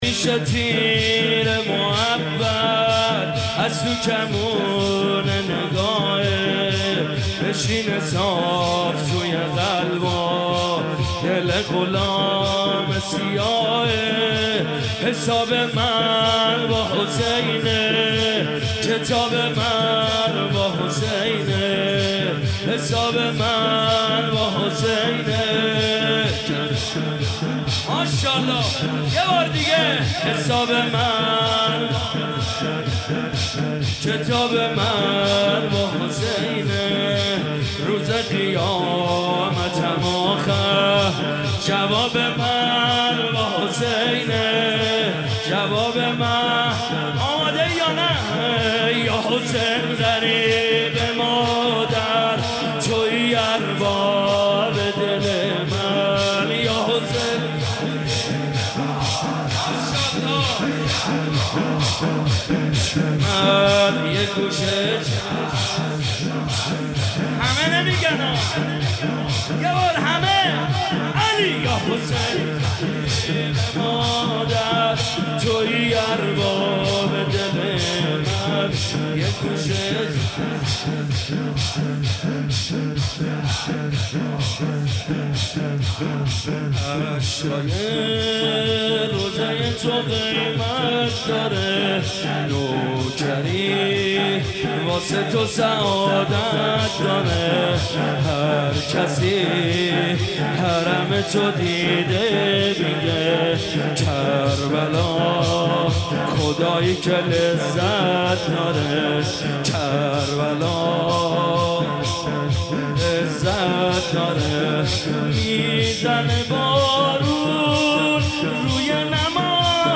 شب ششم محرم95 /هيئت الرضا(ع)
سینه زنی